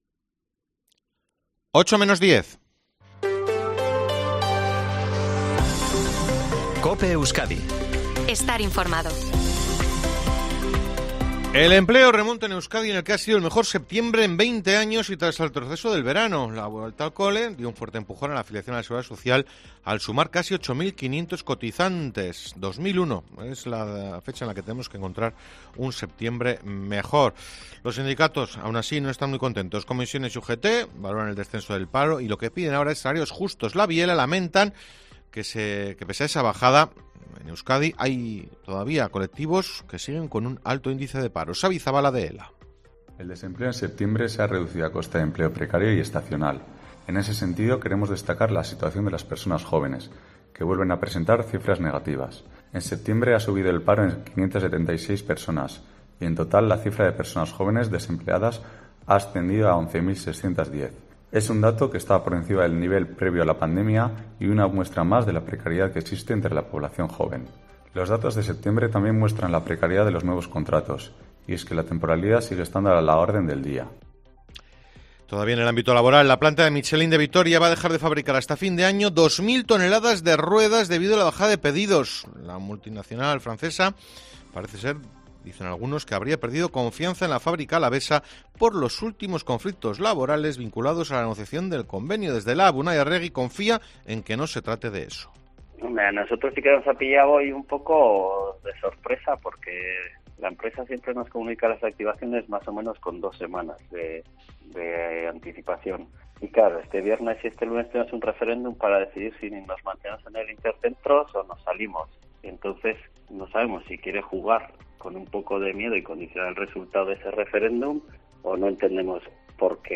INFORMATIVO TARDE COPE EUSKADI 03/10/2023